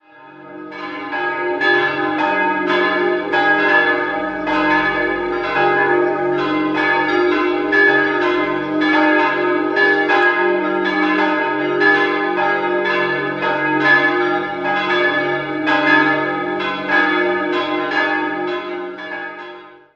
4-stimmiges Geläute: es'-as'-c''-f''
bell
Sehr eigenwilliges historisches Barockgeläute.